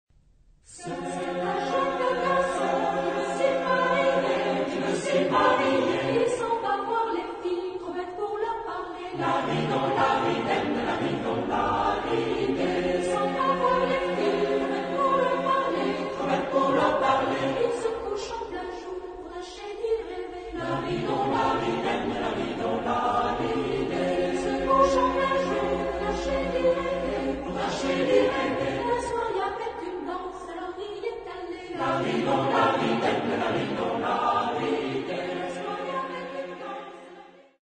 Genre-Style-Form: Secular ; Popular ; Song with repetition
Type of Choir: SATB  (4 mixed voices )
Tonality: F major